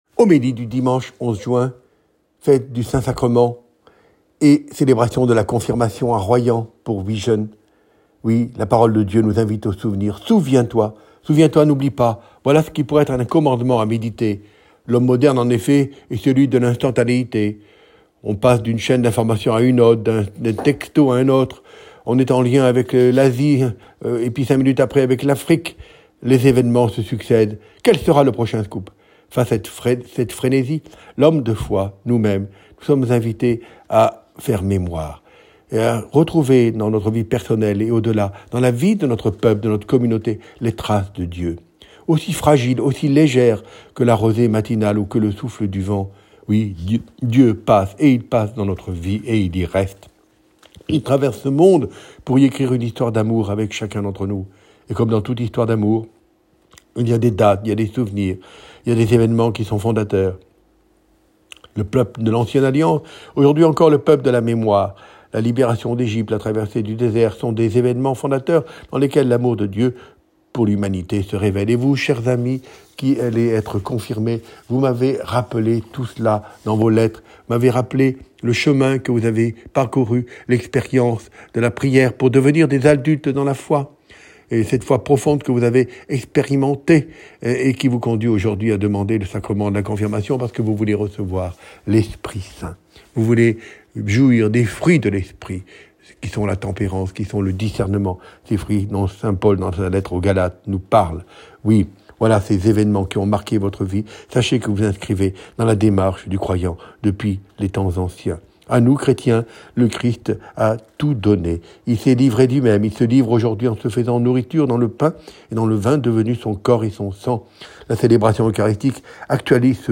Homélie dimanche 11 juin
Ecouter l’homélie de Mgr Colomb, Directeur National des OPM